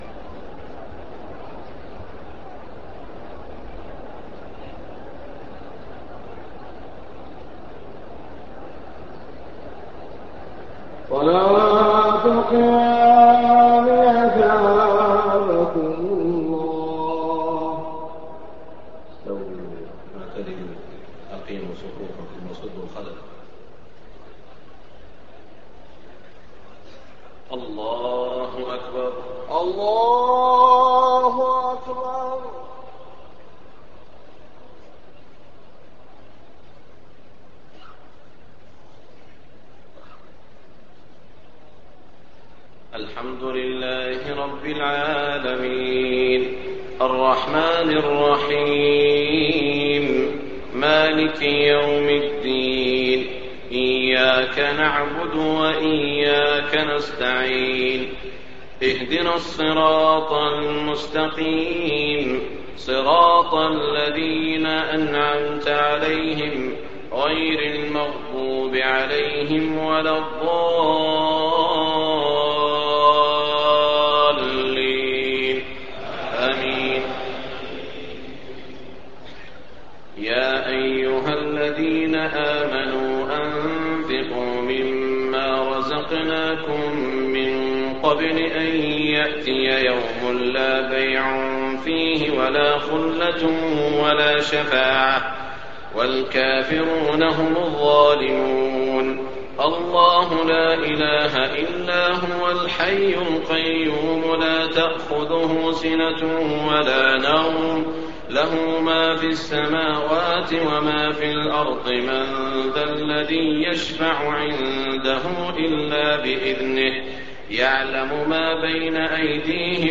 تهجد ليلة 23 رمضان 1424هـ من سورتي البقرة (254-286) و آل عمران (1-32) Tahajjud 23 st night Ramadan 1424H from Surah Al-Baqara and Aal-i-Imraan > تراويح الحرم المكي عام 1424 🕋 > التراويح - تلاوات الحرمين